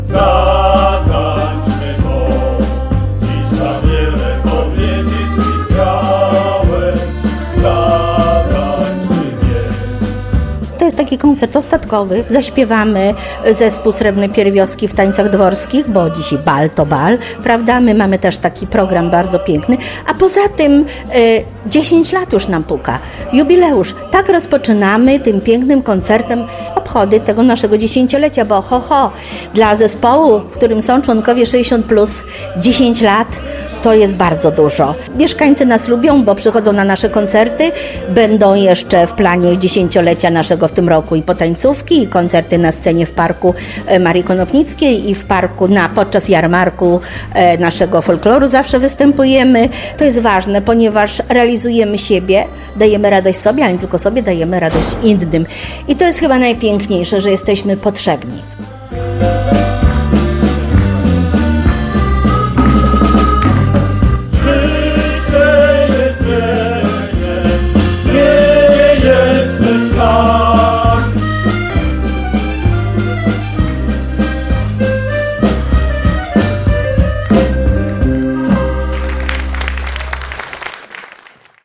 Z tej okazji w Suwalskim Ośrodku Kultury odbył się koncert. Zebrani mogli posłuchać karnawałowych przebojów i polskich hitów.